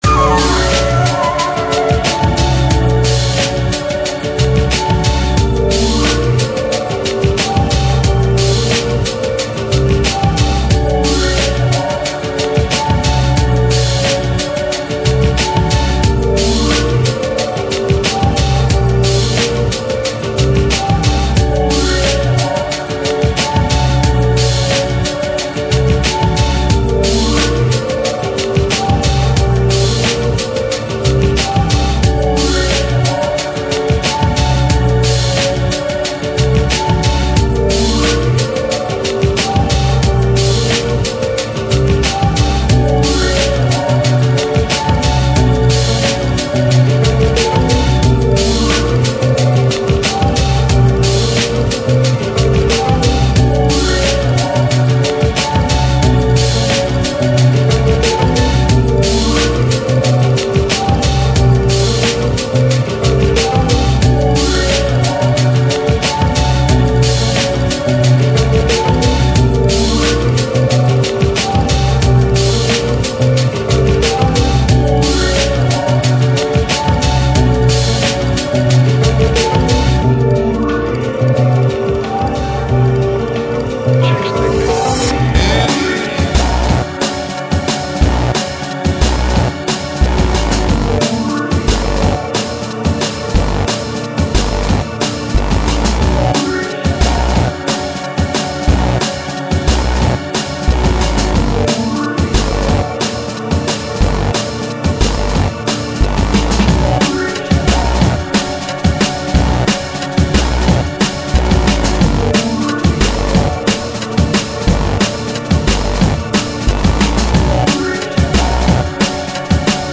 drum'n'bass
Here are MP3 files featuring our tracks and livesets: